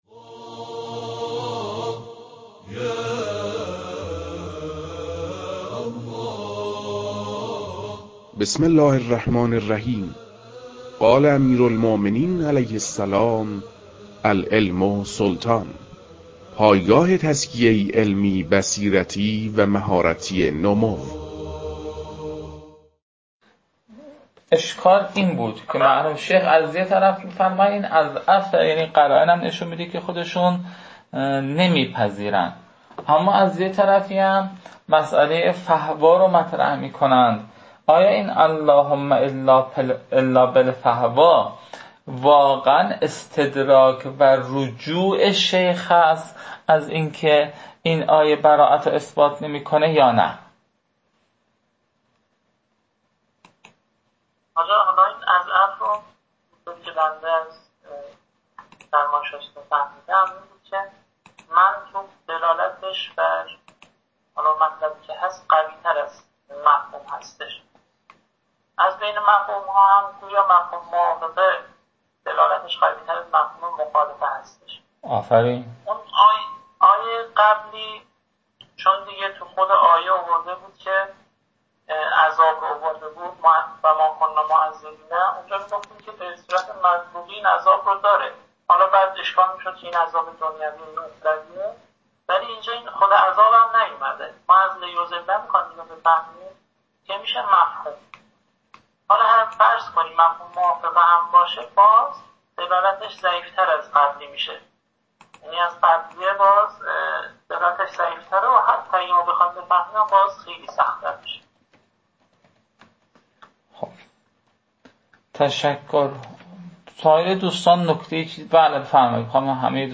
این فایل ها مربوط به تدریس مبحث برائت از كتاب فرائد الاصول (رسائل) متعلق به شیخ اعظم انصاری رحمه الله می باشد